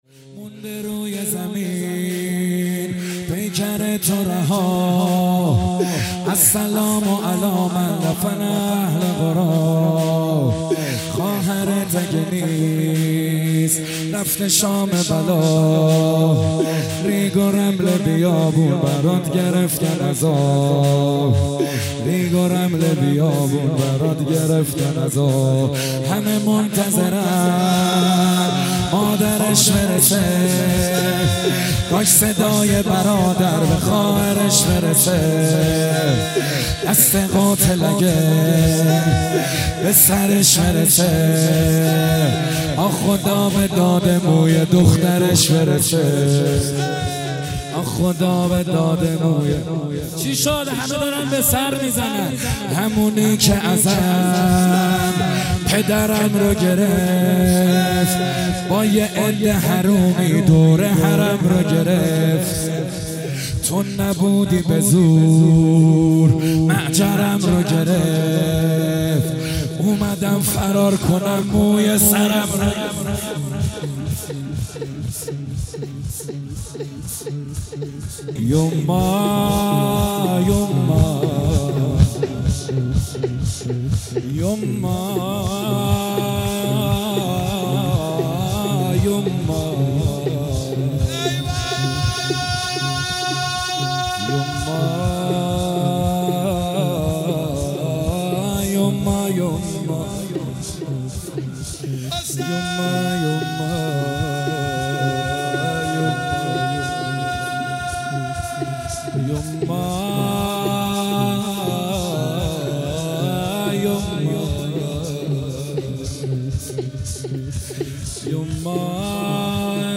محرم99 - مهدیه امام حسن مجتبی(ع) - روز دوم - شور - مونده روی زمین